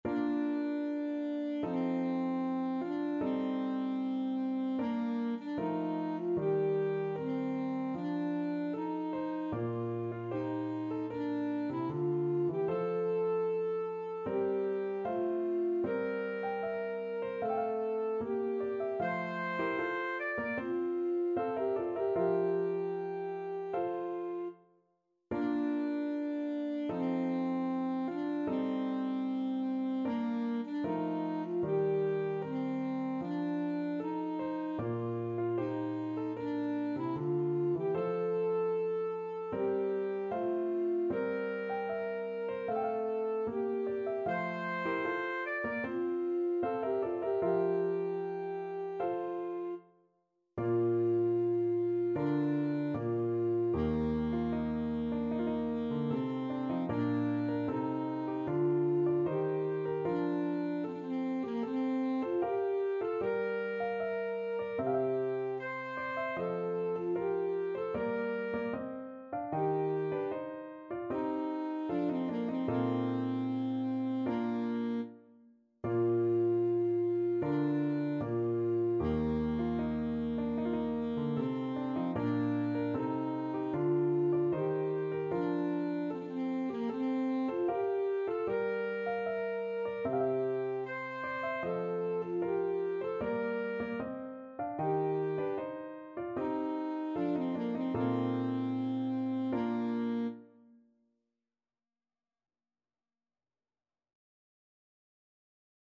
Classical Mozart, Wolfgang Amadeus March from Idomeneo, Act 3 Alto Saxophone version
Alto Saxophone
Bb major (Sounding Pitch) G major (Alto Saxophone in Eb) (View more Bb major Music for Saxophone )
4/4 (View more 4/4 Music)
Andante =76
march_idomeneo_act3_ASAX.mp3